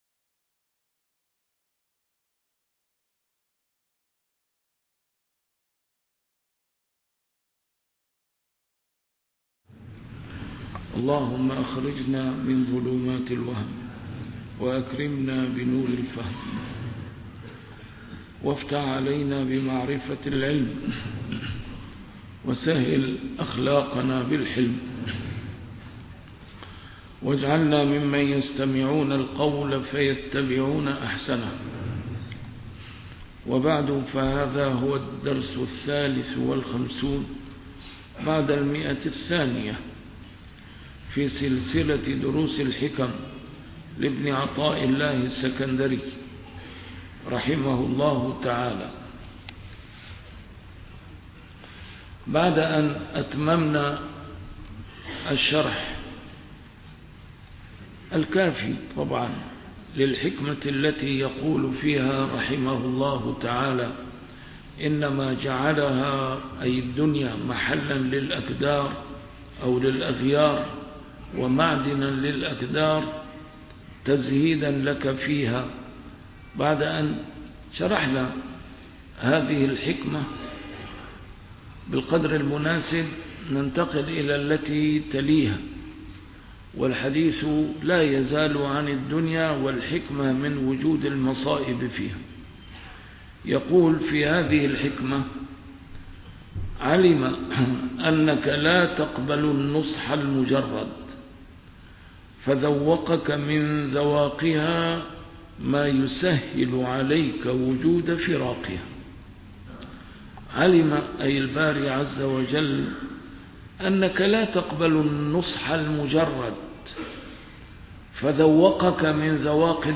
A MARTYR SCHOLAR: IMAM MUHAMMAD SAEED RAMADAN AL-BOUTI - الدروس العلمية - شرح الحكم العطائية - الدرس رقم 252 شرح الحكمة رقم 229